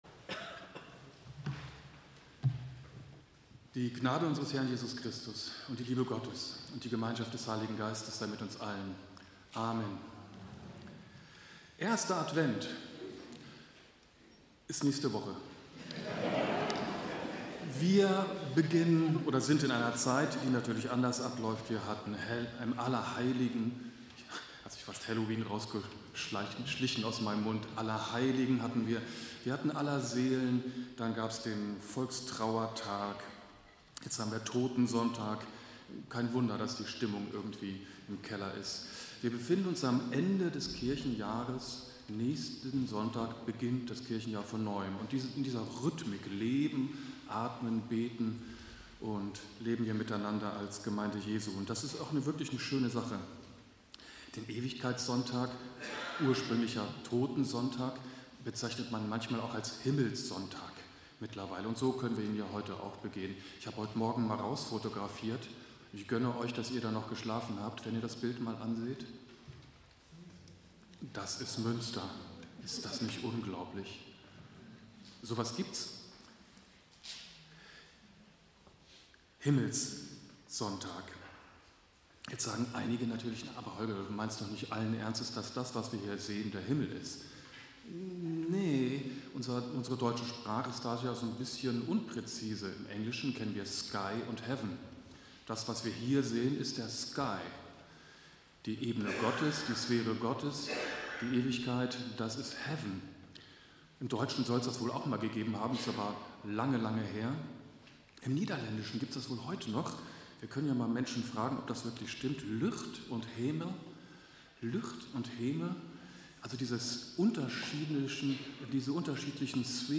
Predigt-am-Ewigkeitssonntag-–-Sehnsucht-nach-dem-Himmel_-–-2.-Korinther-51-10-online-audio-converter.com_.mp3